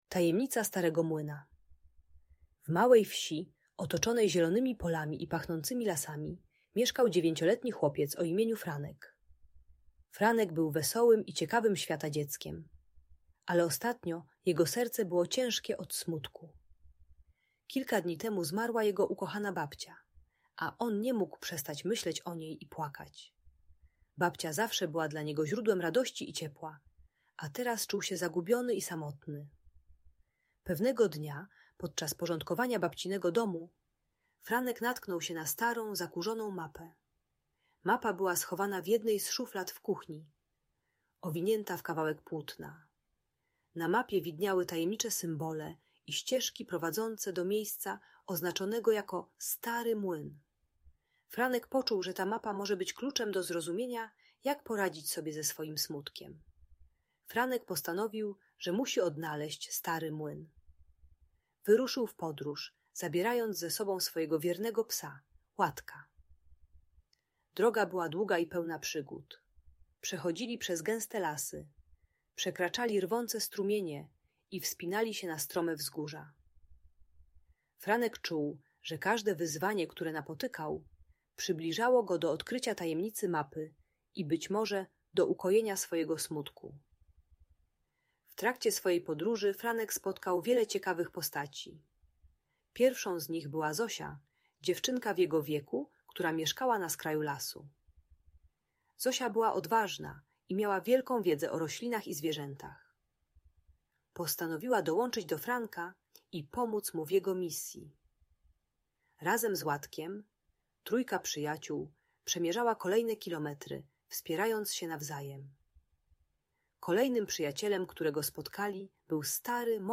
Tajemnicza historia Franka i Starego Młyna - Audiobajka dla dzieci